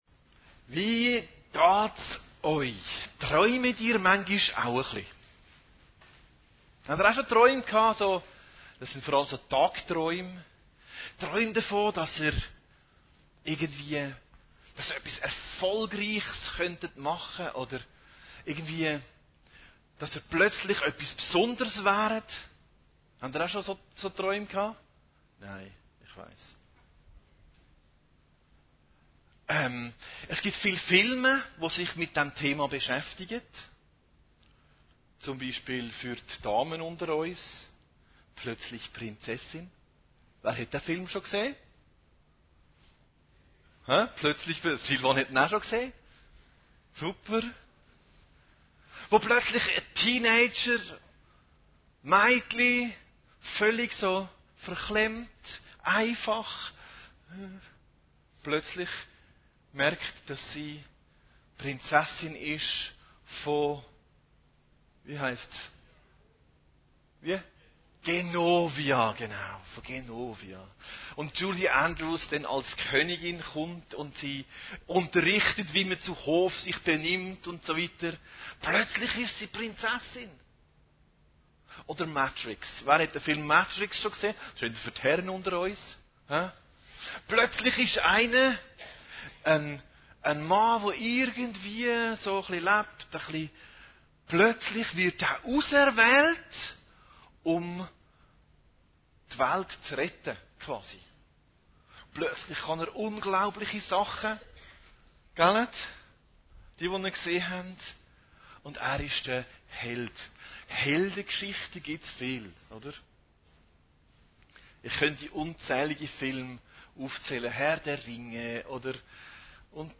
Predigten Heilsarmee Aargau Süd – Jesus geht andere Wege